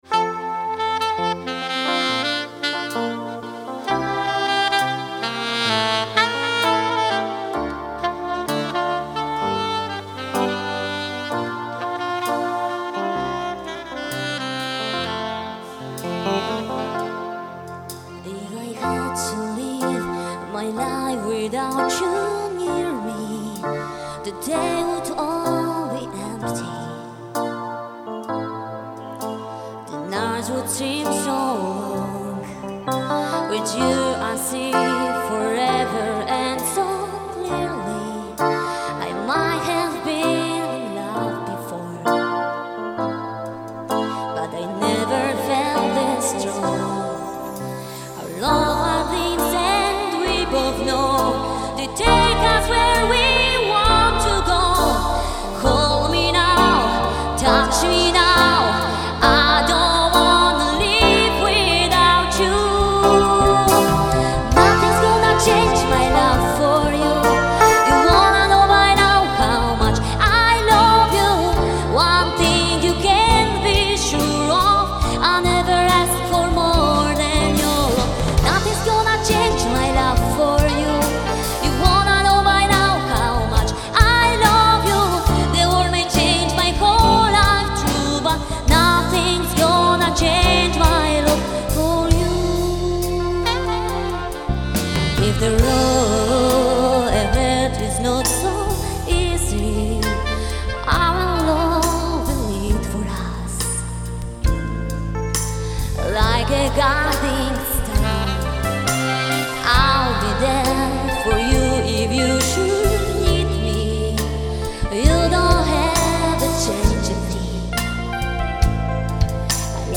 Nagrania LIVE (z koncertu):